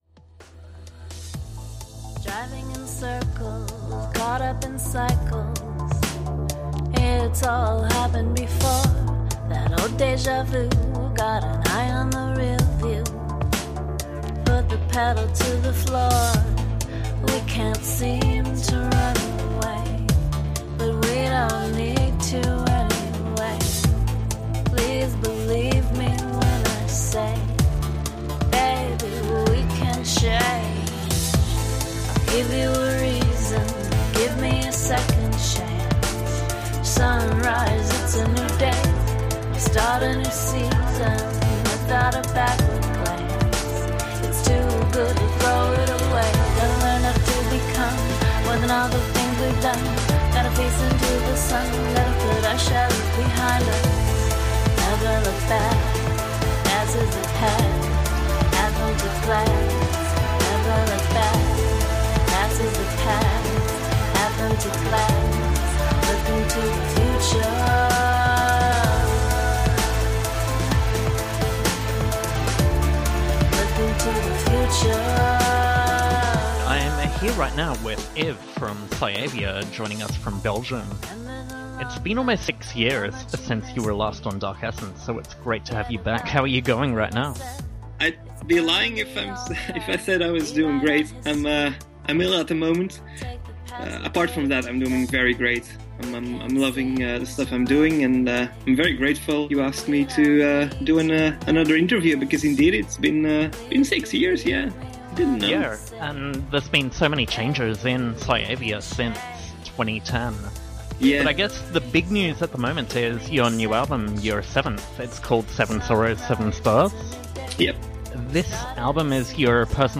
Radio Interview by Australian Radio 4ZZZFM / Dark Essence Show